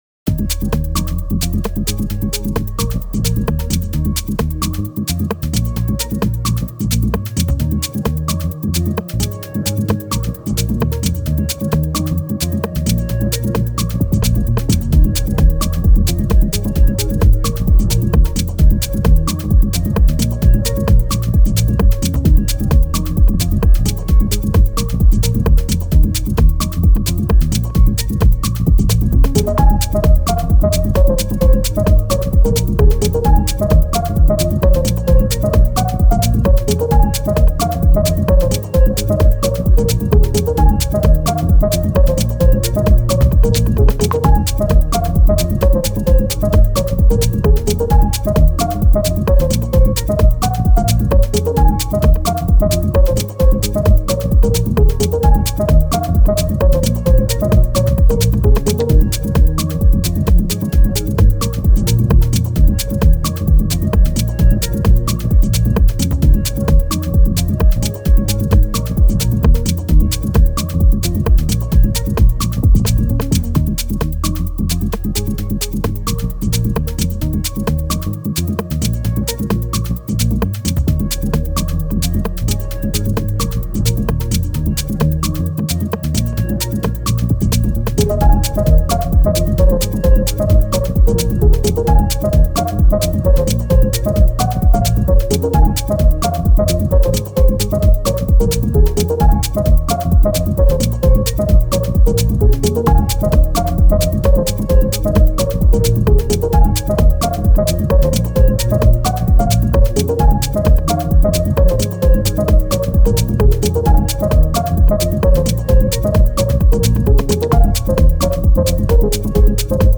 フリーBGM